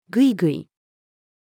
without-hesitation-female.mp3